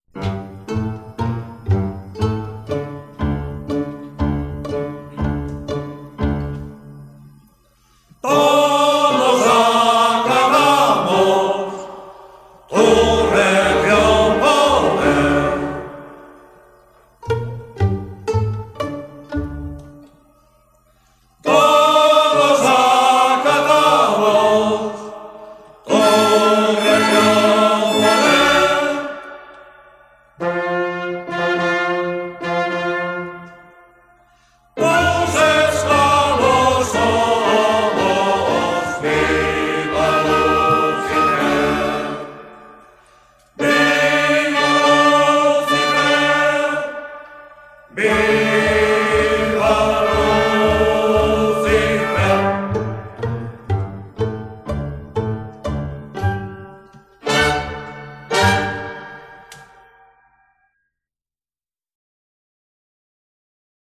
Sarsuela pastoril
Enregistrat el 6 de juliol de 1977 al Centre Catòlic d'Olot.